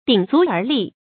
注音：ㄉㄧㄥˇ ㄗㄨˊ ㄦˊ ㄌㄧˋ
鼎足而立的讀法